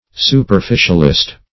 Search Result for " superficialist" : The Collaborative International Dictionary of English v.0.48: Superficialist \Su`per*fi"cial*ist\, n. One who attends to anything superficially; a superficial or shallow person; a sciolist; a smatterer.